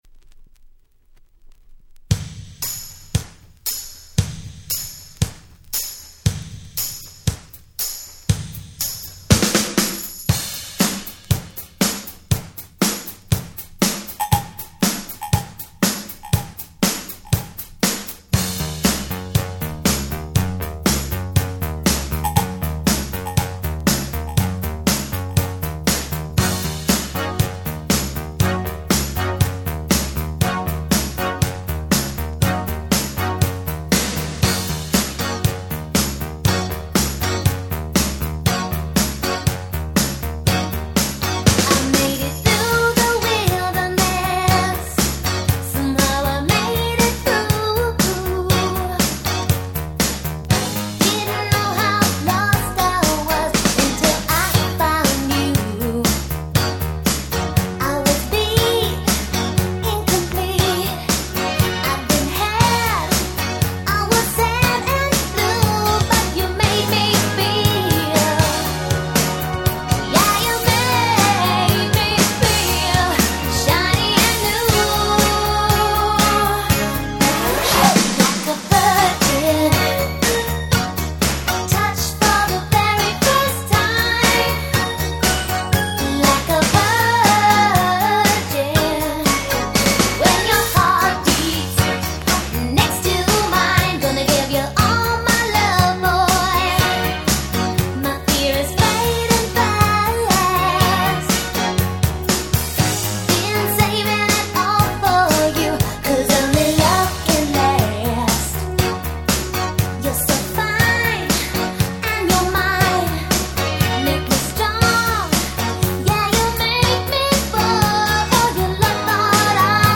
80's R&B/Disco Classic !!